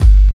137 KICK 2.wav